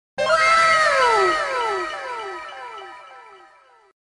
Звуки вау
Звук удивления женским голосом в духе японской анимации